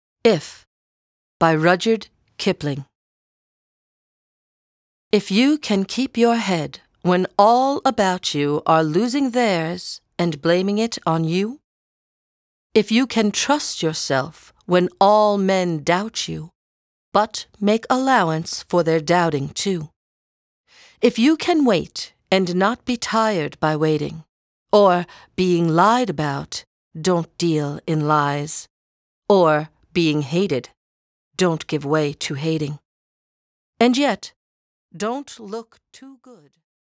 (Narrator)